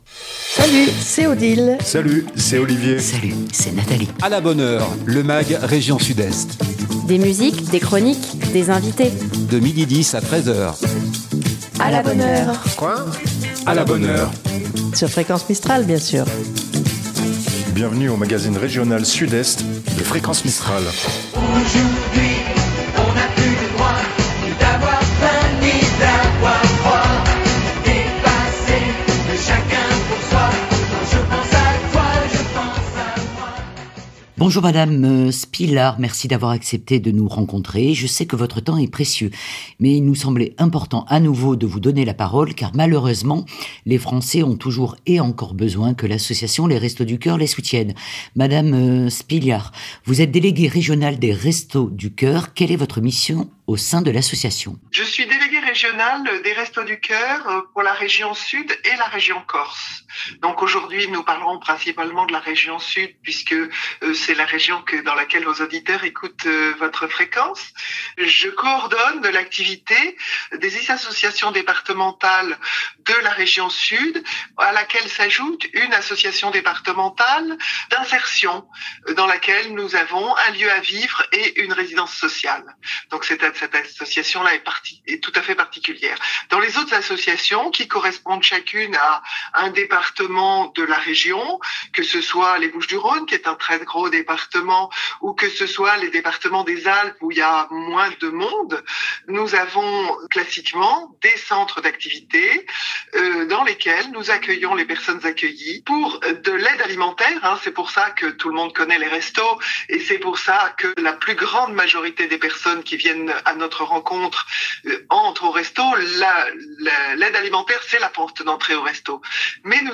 01 Les Restos du Coeur.mp3 (40.44 Mo) Dans le magasine régional " A LA BONNE HEURE " 12h10 - 13h00 , ce mercredi nous recevons l'association des Restos du Coeur. Nos invités évoquent la situation alarmante en région PACA et dans les Alpes-de-Haute-Provence.